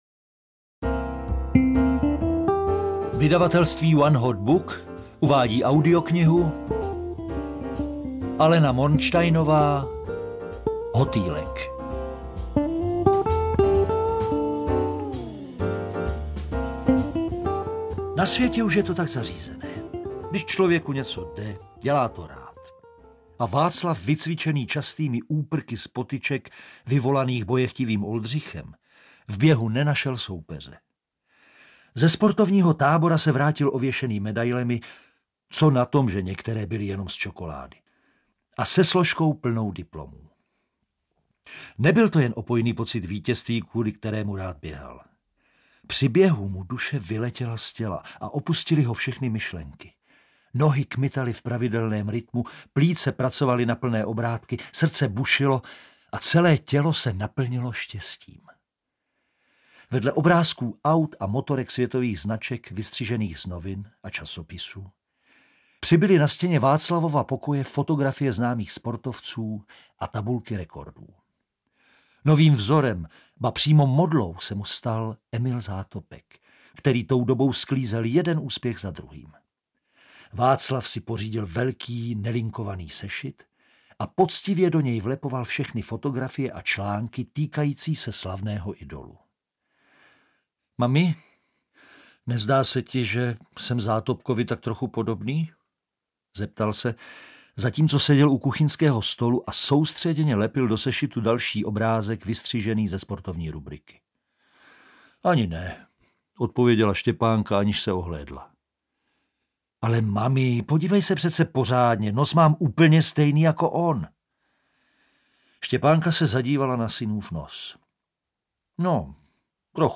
Hotýlek audiokniha
Ukázka z knihy